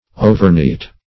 Overneat \O"ver*neat"\, a. Excessively neat.